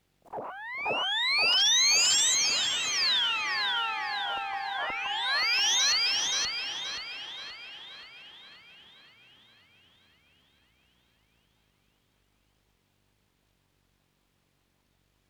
Angry Cat 2.wav